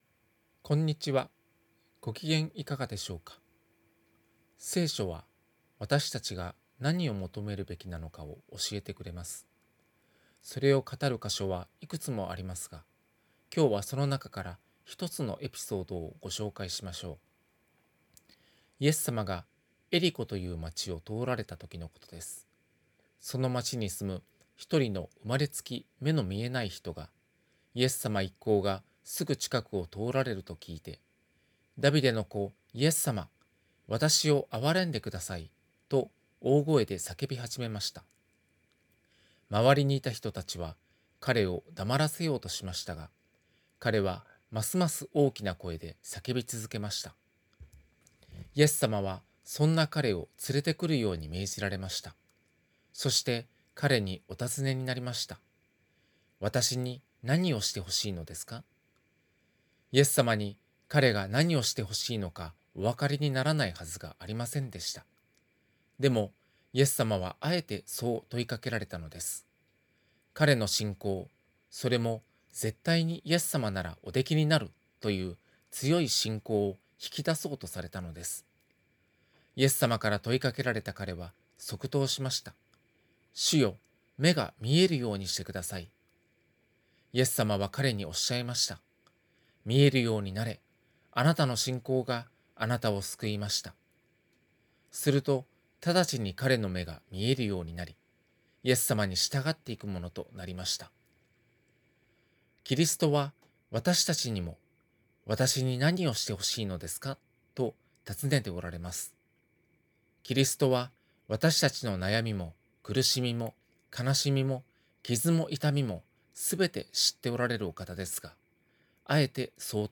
電話で約３分間のテレフォンメッセージを聞くことができます。